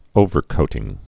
(ōvər-kōtĭng)